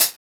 Hat (49).wav